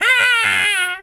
monkey_hurt_scream_08.wav